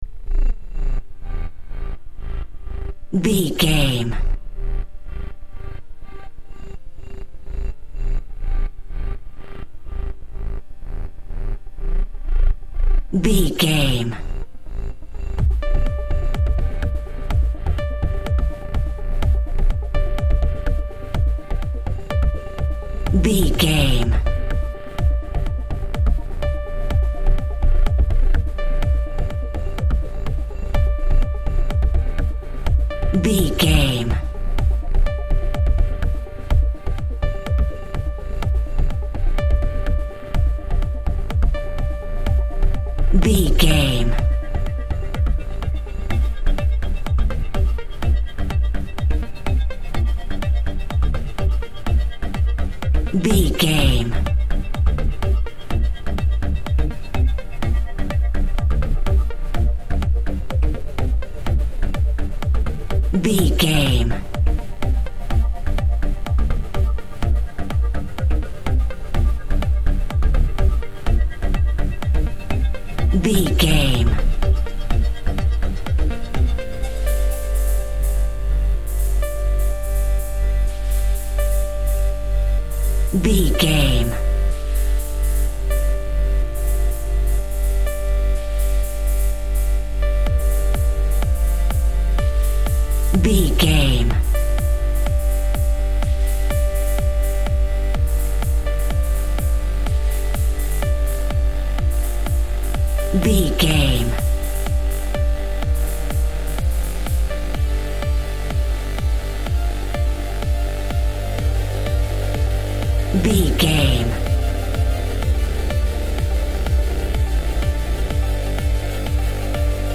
Epic / Action
Fast paced
Aeolian/Minor
Fast
dark
futuristic
groovy
aggressive
synthesiser
drum machine
house
techno
trance
synth leads
synth bass
upbeat